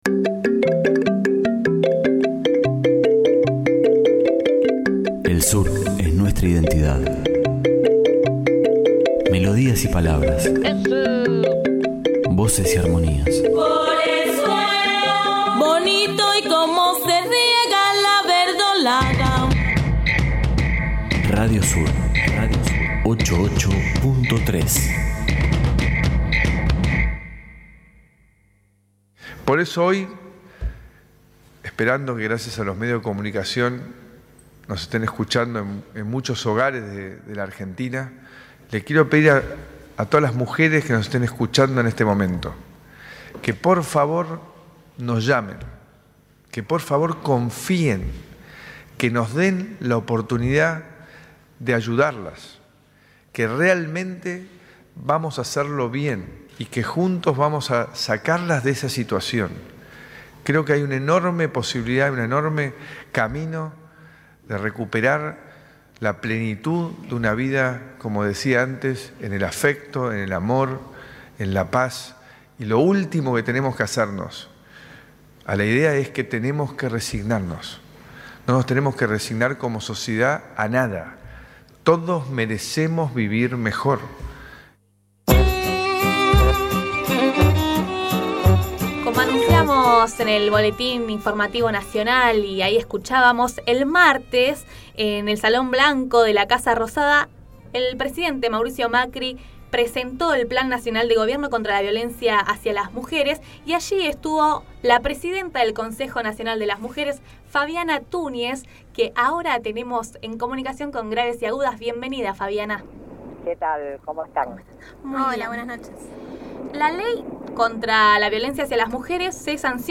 Entrevista Fabiana Tuñez | Tenemos un plan
El pasado 26 de julio el Gobierno presentó un plan para erradicar la violencia contra las mujeres. Fabiana Tuñez conversó con Graves y agudas acerca de los objetivos del plan.